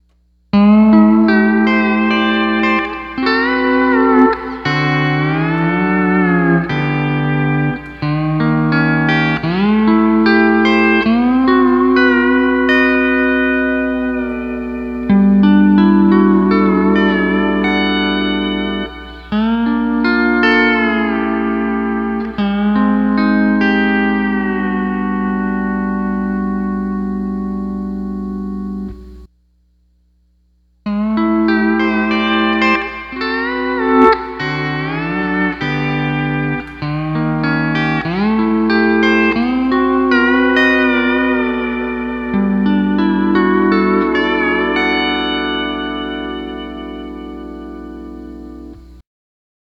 Simple Demo Of P4 Alone